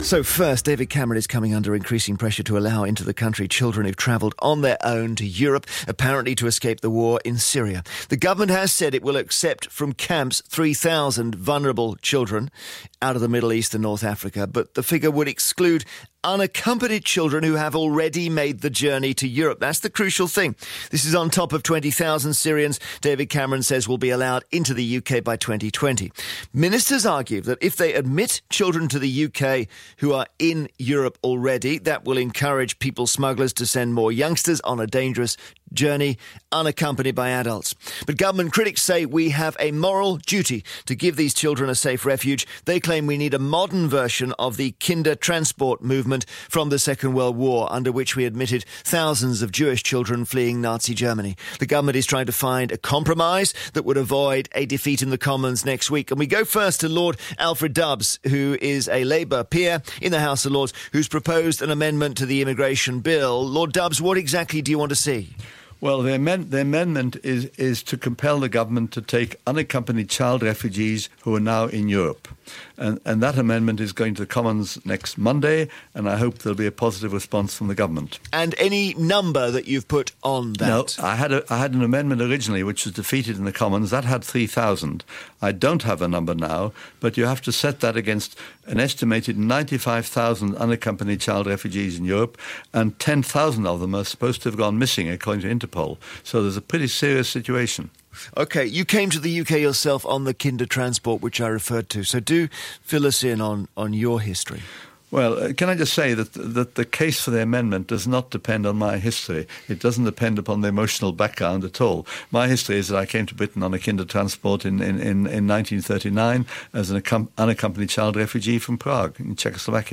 Radio 2's Jeremy Vine show, 4 May 2016